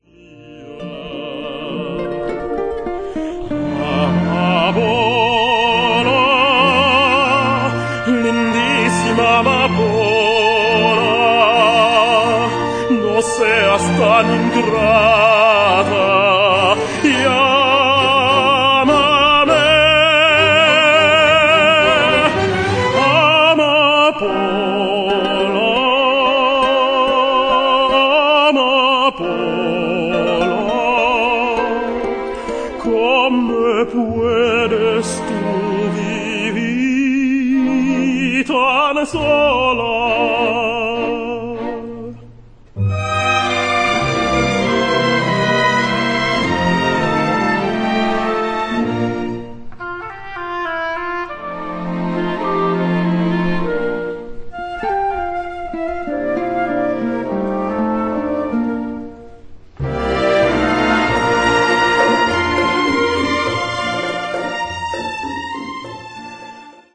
ประเภทเพลงไทยสากล
เจ้าของเสียงเทเนอร์ระดับแถวหน้า